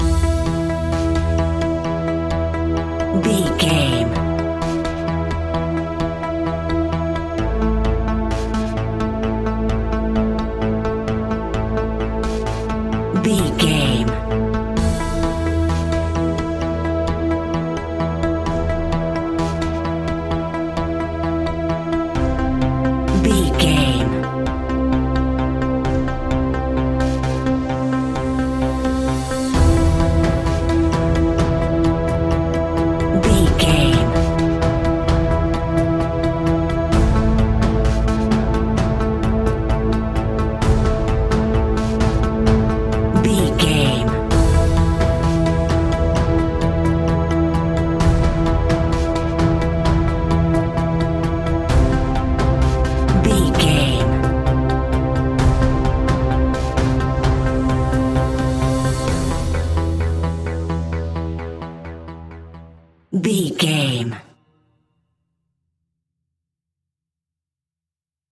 Aeolian/Minor
scary
ominous
dark
haunting
eerie
futuristic
synthesiser
drums
ticking
electronic music
electronic instrumentals